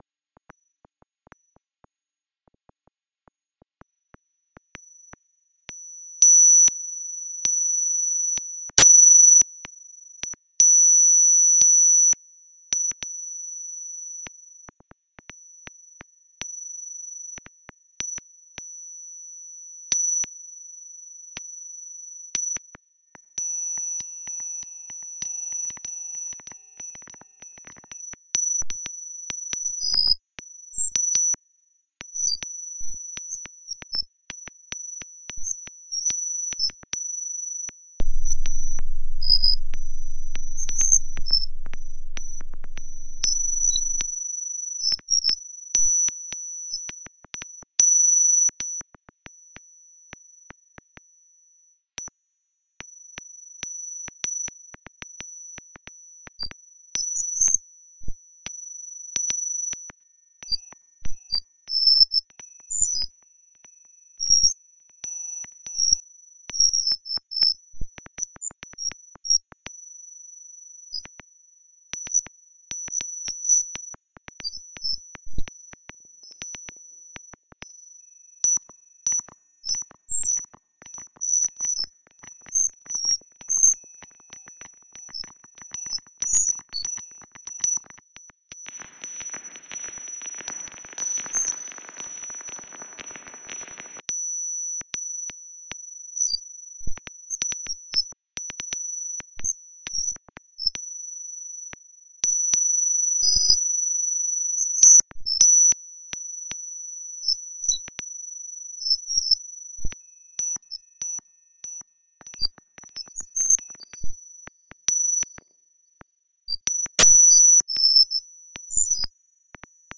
Marco Polo airport landing reimagined